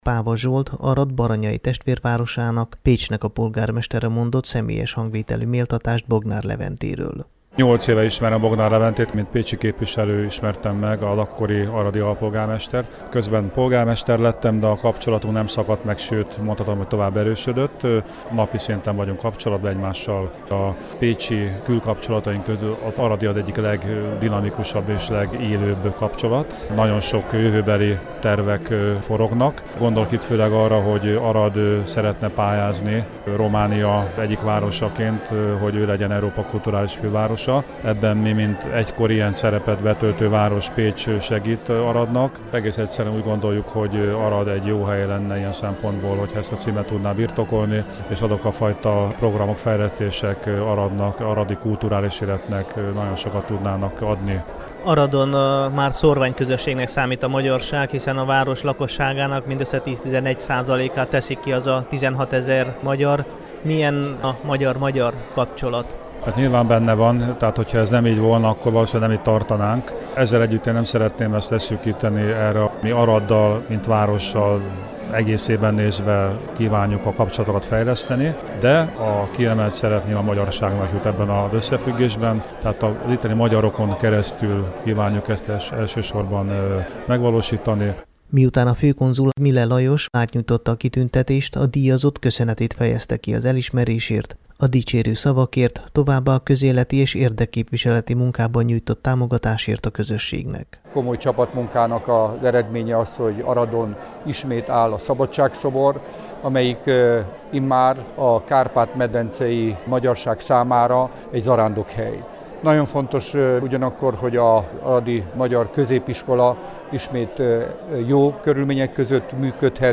Az augusztus elején odaítélt kitüntetést kedd délután az aradi Jelen Házban vehette át Bognár Levente.